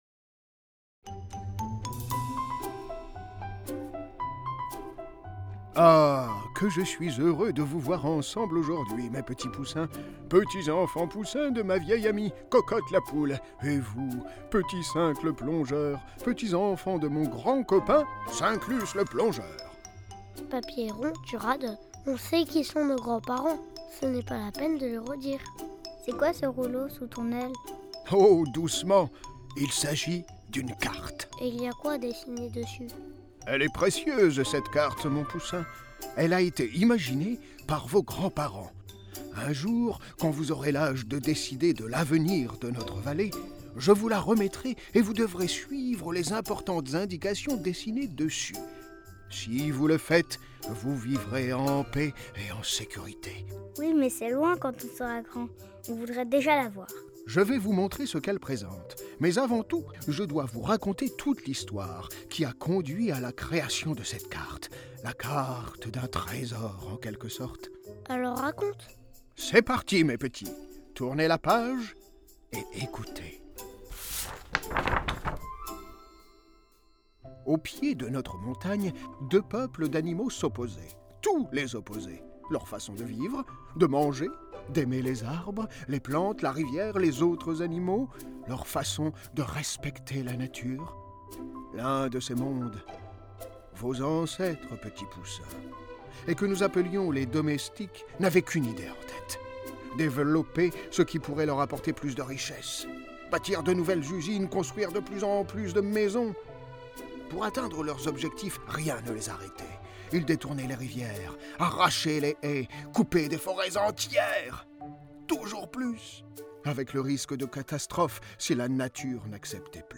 Histoire d'une riviere un audio livre pour les enfants de 3 à 7 ans